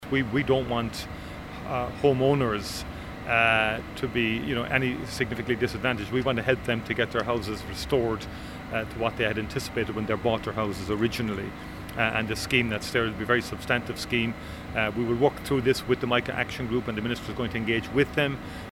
Speaking on his way into Cabinet, Micheál Martin said substantive changes will be made: